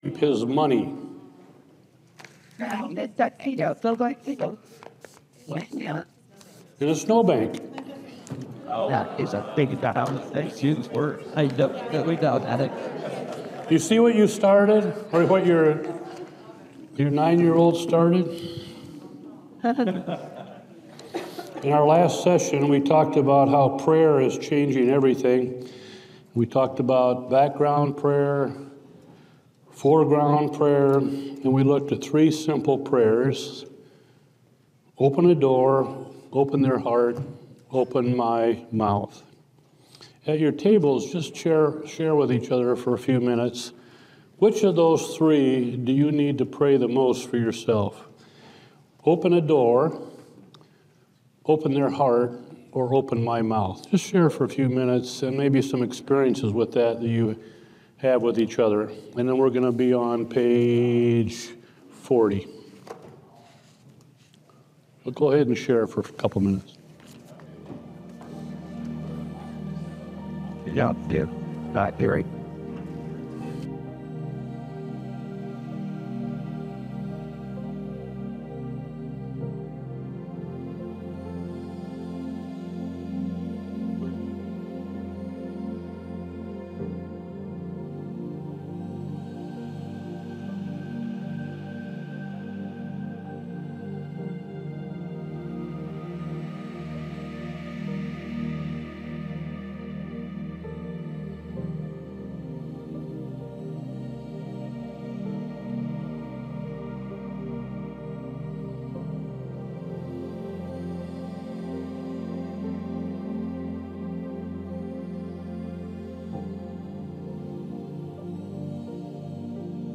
Seminar recordings from Evangelism Shift.